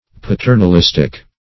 Search Result for " paternalistic" : Wordnet 3.0 ADJECTIVE (1) 1. benevolent but sometimes intrusive ; The Collaborative International Dictionary of English v.0.48: paternalistic \pa*ter`nal*ist"ic\ adj.